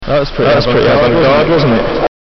Among the several recordings that were manipulated in "Several Species.." there is at least one (distinguishable) backward message: a voice saying "that was pretty avant-garde, wasn't it?".